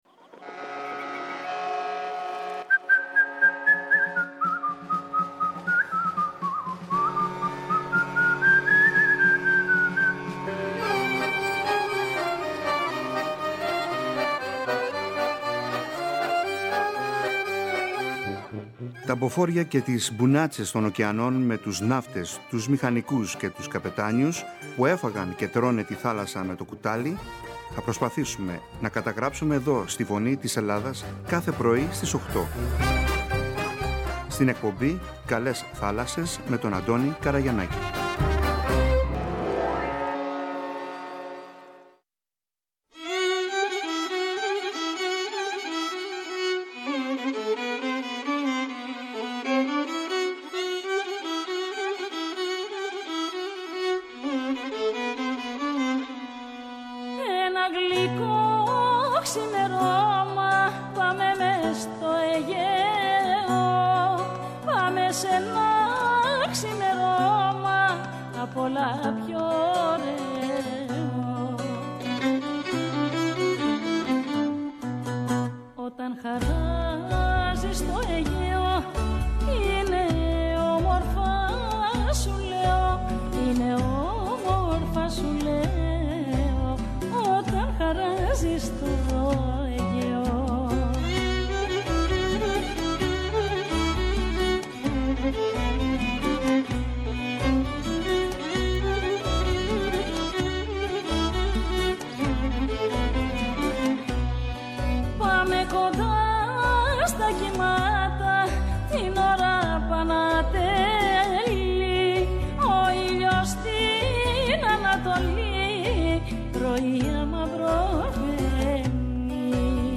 Μας λέει για τις διακρίσεις της ποιητικής συλλογής και μέχρι που έχει φθάσει, για το αγαπημένο της νησί την Σκύρο. Ξεχώρισε και μας διάβασε κάποια από τα ξεχωριστά αυτά ναυτικά ποιήματα και πολλά άλλα.